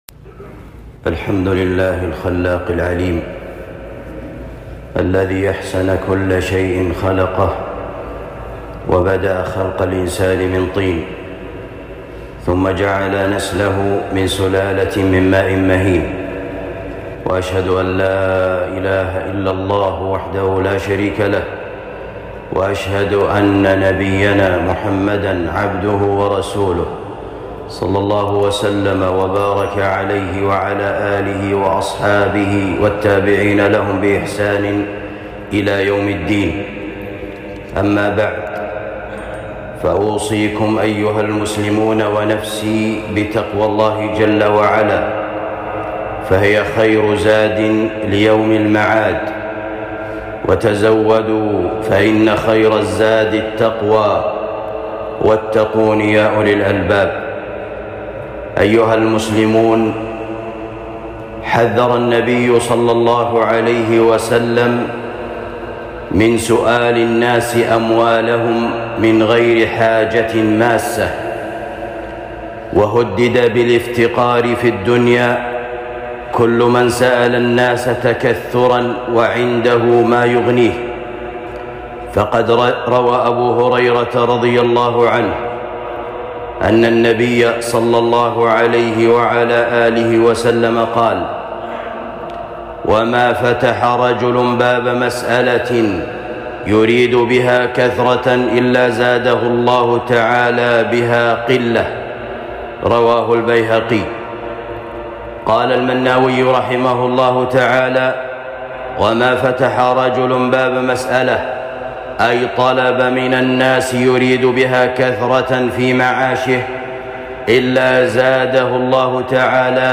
خطبة جمعة بعنوان تنبيهات حول المتسولين